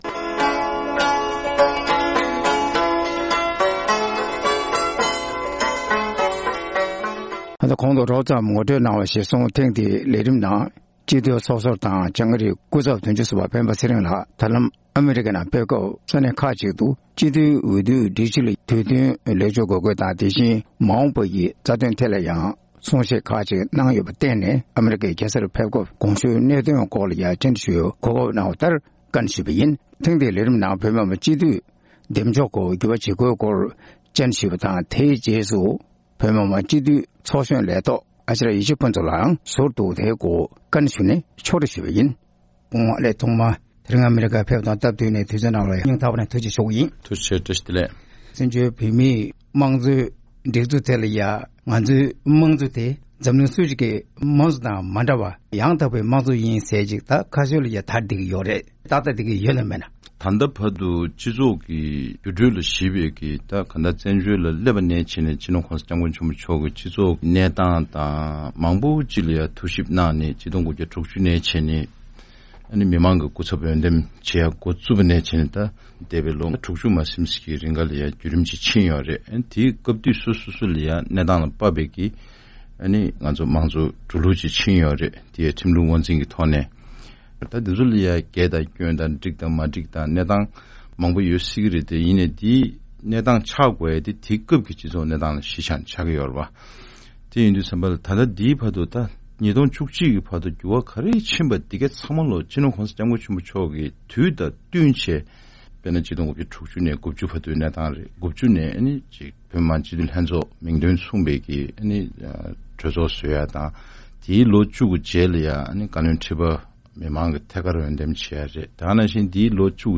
བཀའ་འདྲི་ཞུས་ནས་ཕྱོཌ་སྒྲིག་ཞུས་ཞིག་ལ་གསན་རོགས༎